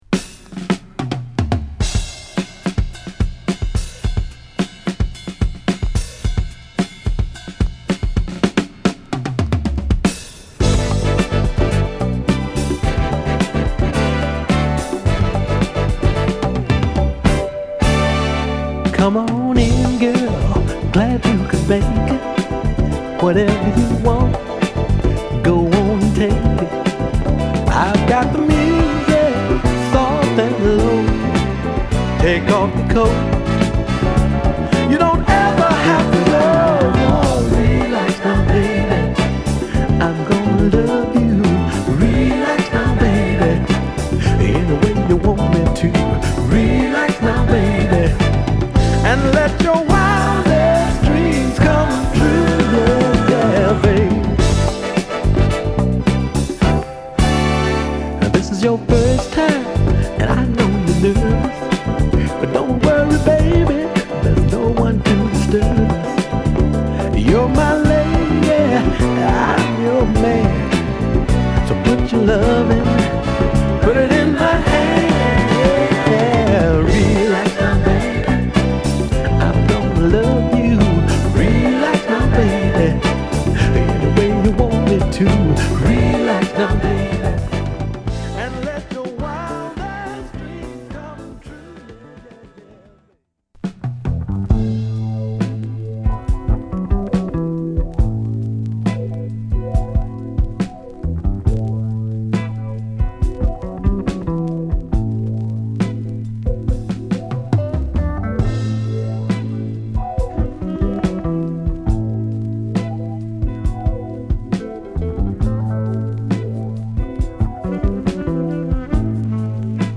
アルバム通して心地よいグッド・ソウルを満載！